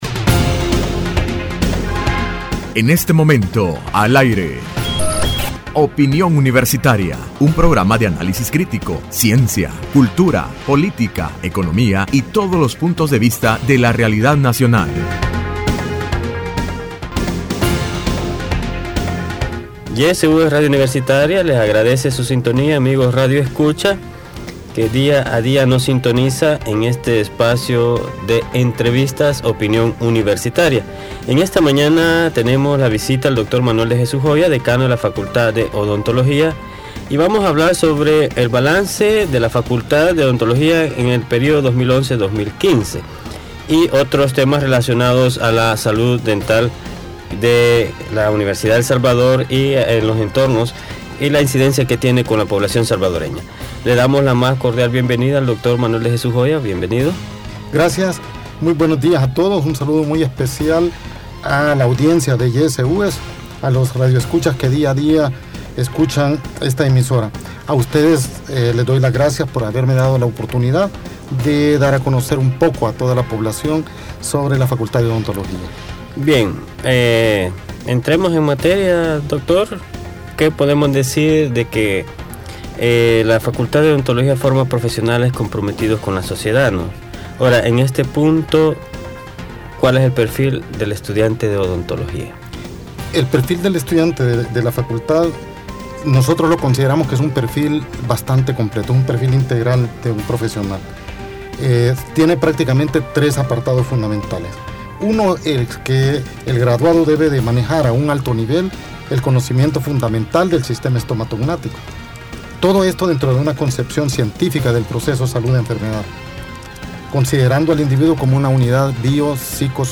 Entrevista Opinión Universitaria (13 mayo 2015): Balance de la Facultad de Odontología gestión 2011-2015